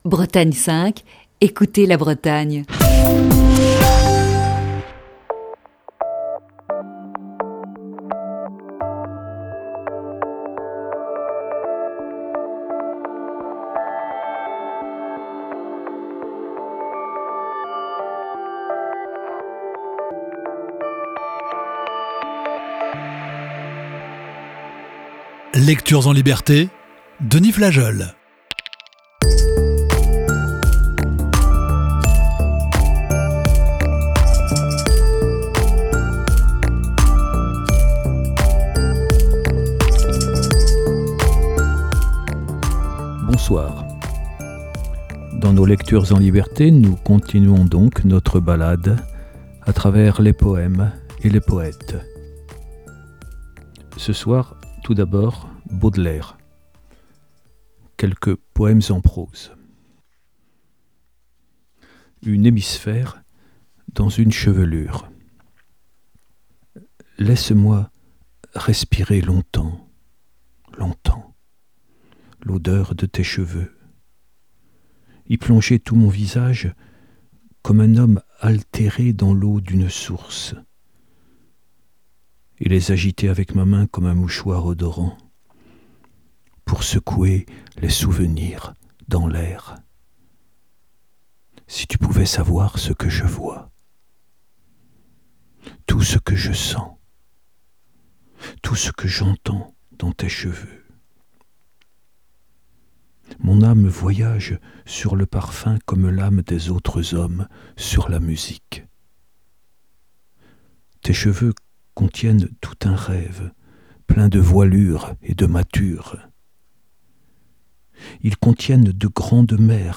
Émission du 19 février 2020.
lit de la poésie